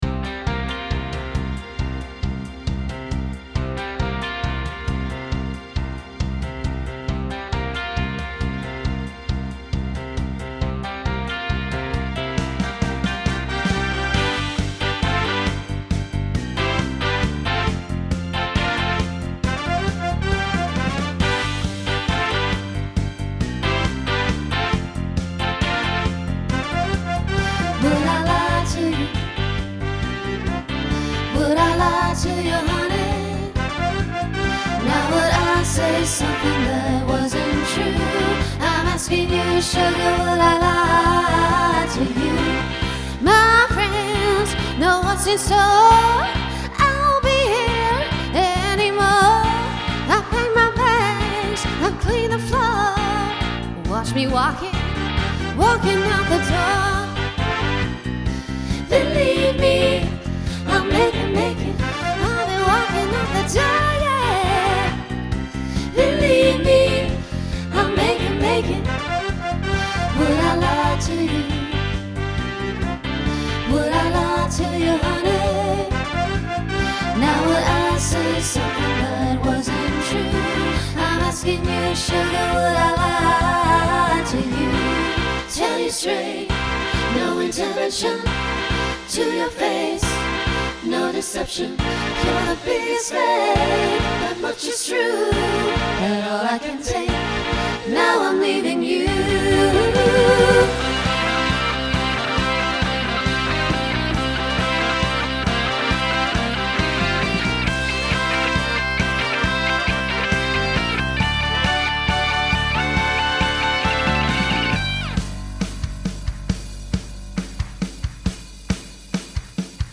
Genre Rock Instrumental combo
Opener Voicing SAB